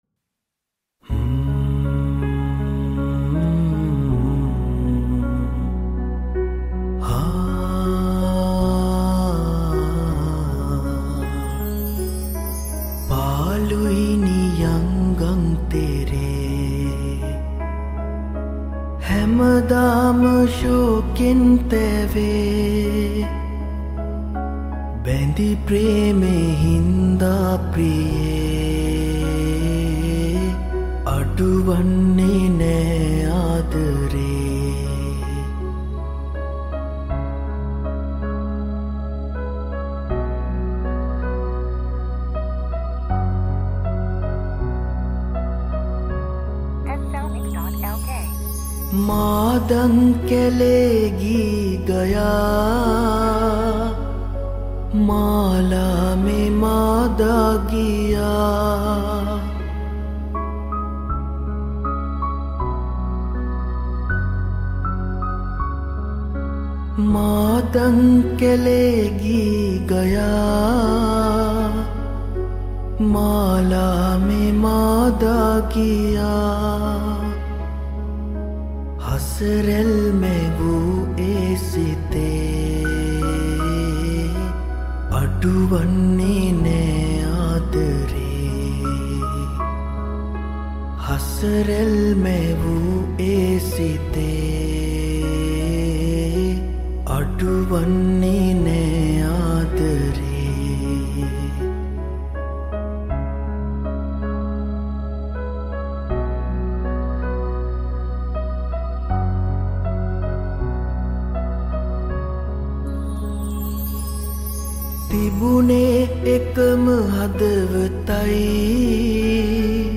Cover Vocals